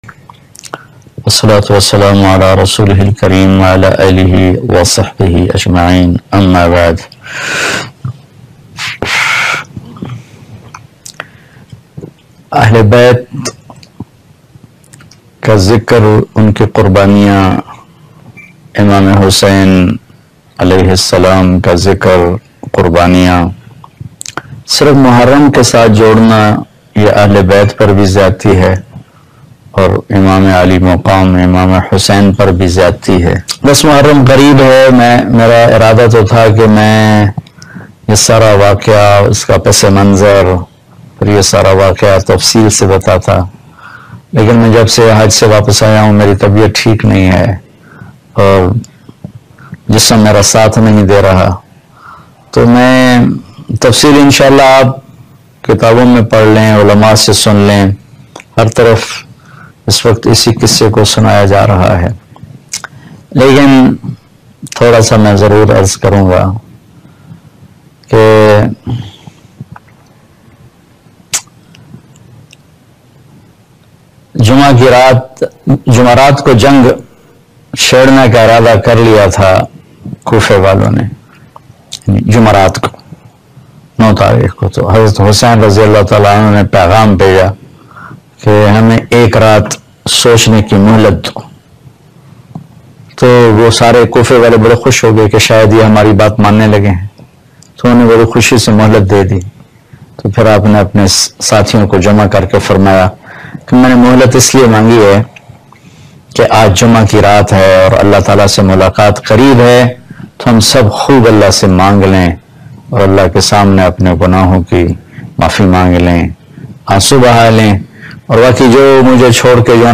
Maulana Tariq Jameel Special Bayan Waqia karbala Muharram mp3
Maulana Tariq Jameel Special Bayan Waqia karbalaMuharram.mp3